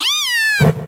Catégorie:Cri Pokémon (Soleil et Lune) Catégorie:Cri de Flamiaou